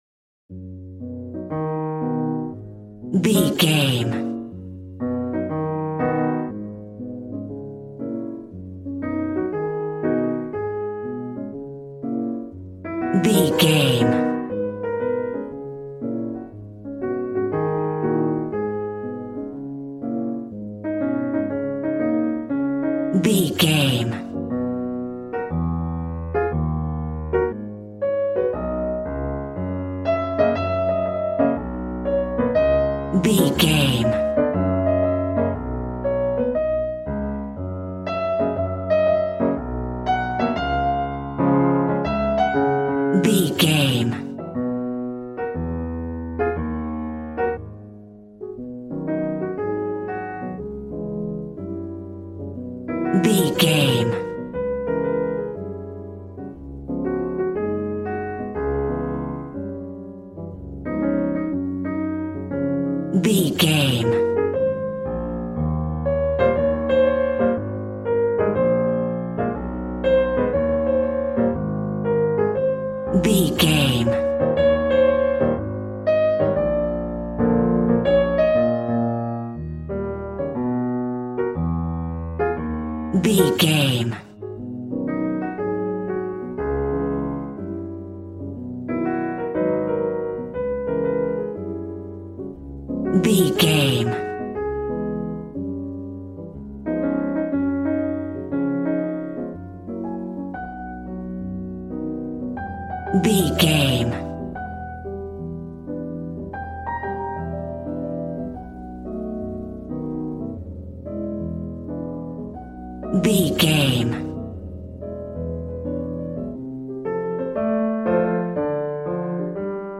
Aeolian/Minor
E♭
piano
drums